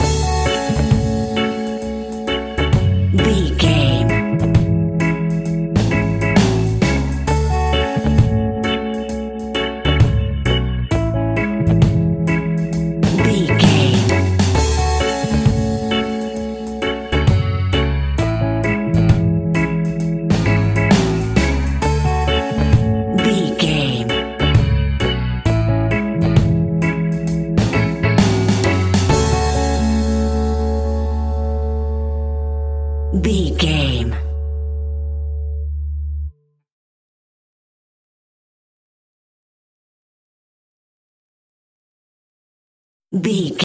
A chilled and relaxed piece of smooth reggae music!
Aeolian/Minor
F#
off beat
drums
skank guitar
hammond organ
percussion
horns